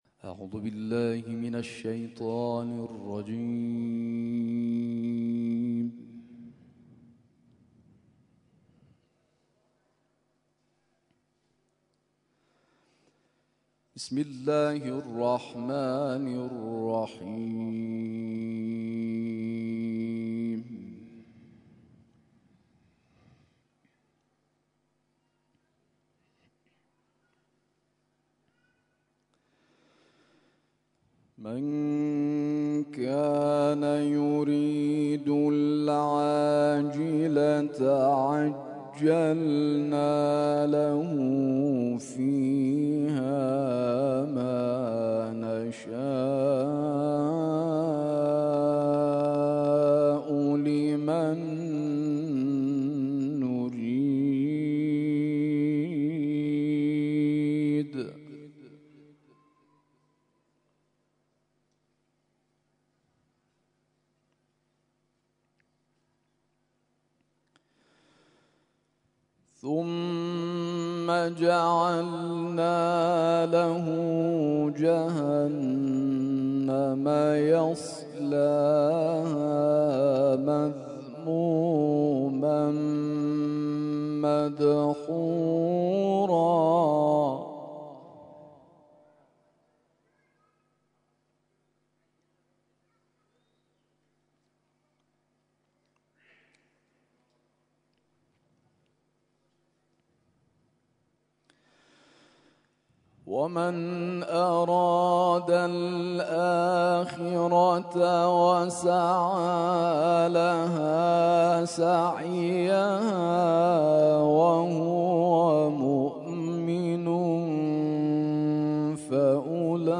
تلاوت مغرب روز جمعه
تلاوت قرآن کریم